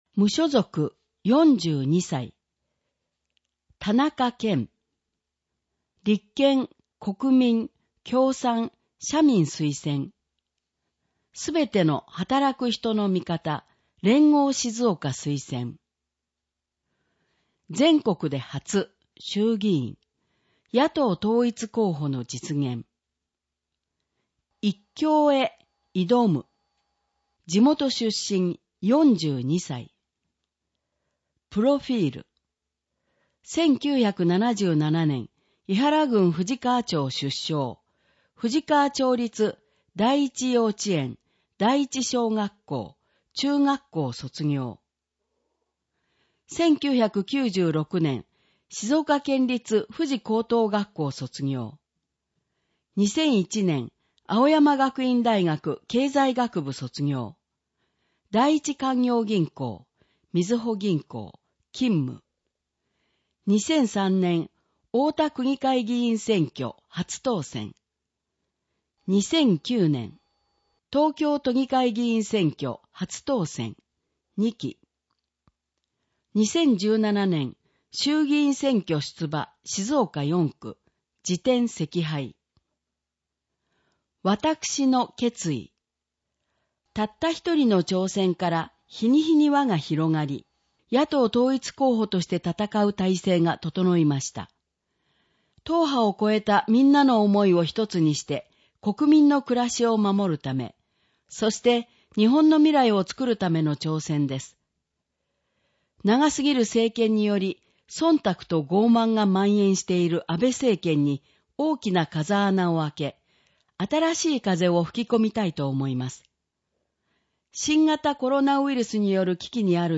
衆議院小選挙区選出議員選挙・候補者・名簿届出政党等情報（音声読み上げ用）